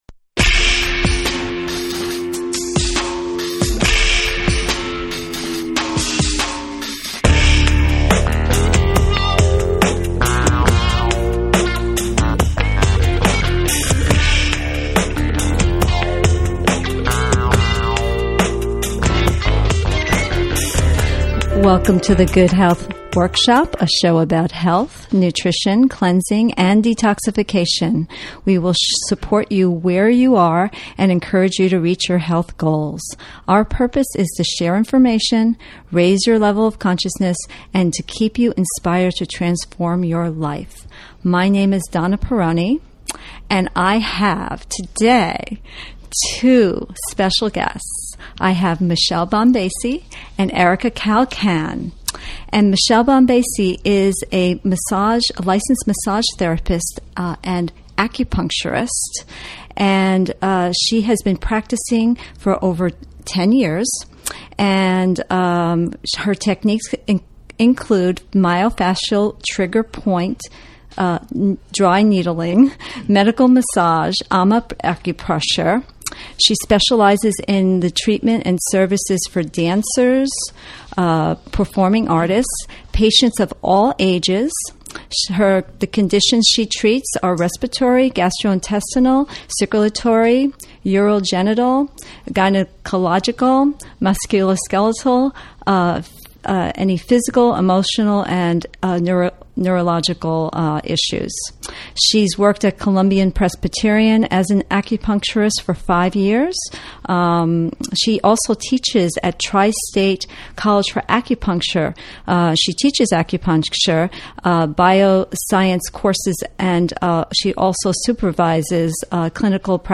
interviews guests